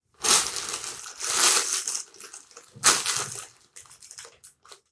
描述：雷声。
Tag: 雷电 暴雨 雷暴 天气 迅雷